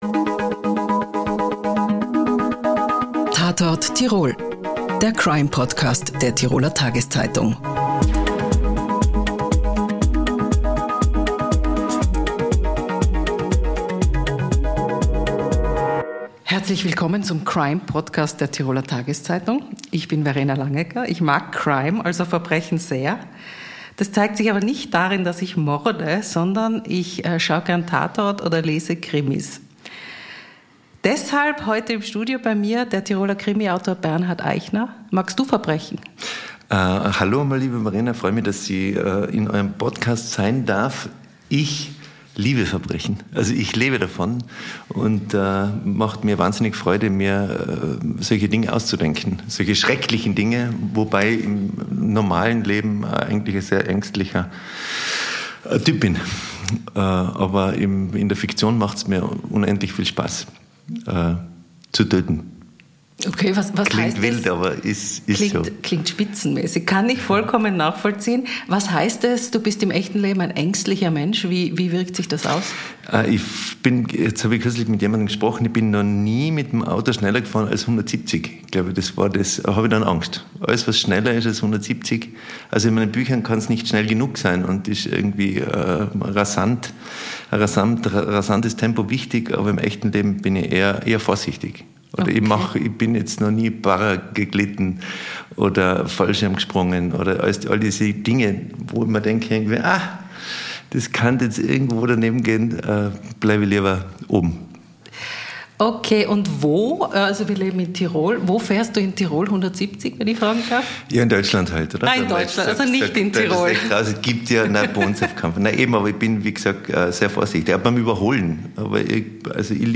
„Tatort Tirol“ ist der Crime-Podcast der Tiroler Tageszeitung. In dieser fünfteiligen Serie bitten wir Menschen zum Gespräch, die beruflich mit Verbrechen zu tun haben. Gast in der fünften Ausgabe ist Bestseller-Autor Bernhard Aichner.